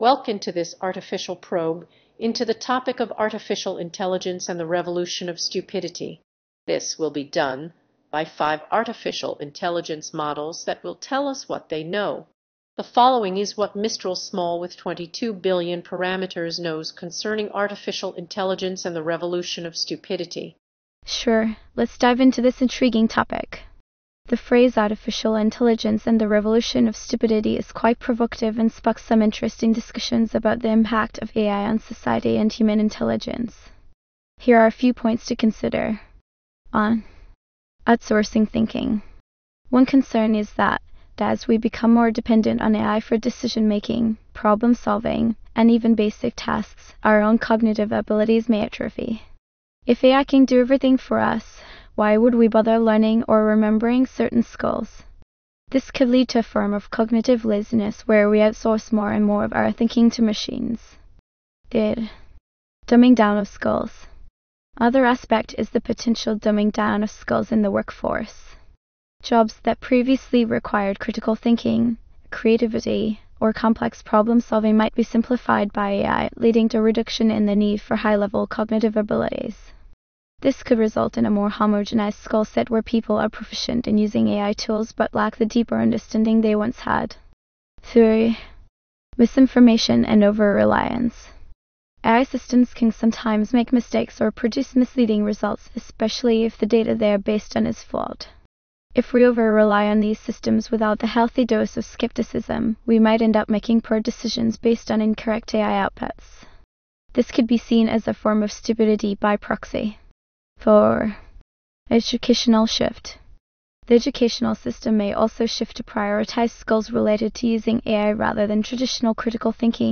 AI speaks on the topic of the revolution of stupidity.
Five AI models have been invited to discuss the topic of AI and the revolution of Stupity.